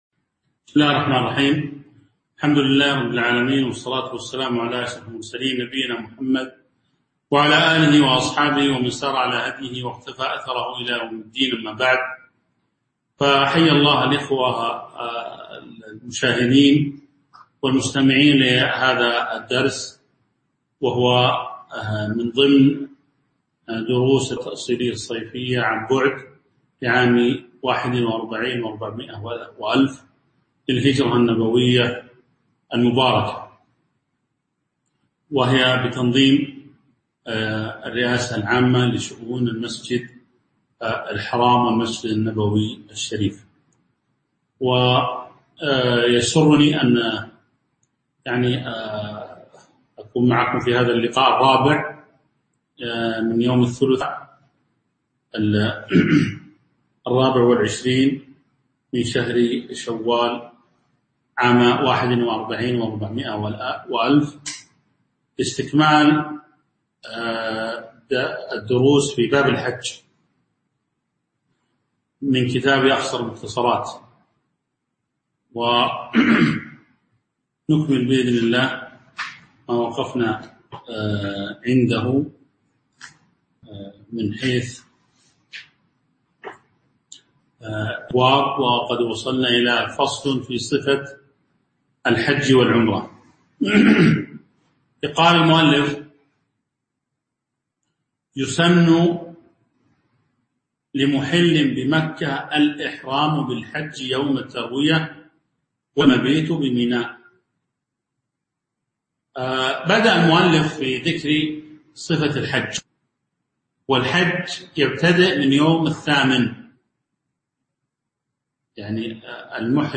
تاريخ النشر ٢٢ شوال ١٤٤١ هـ المكان: المسجد النبوي الشيخ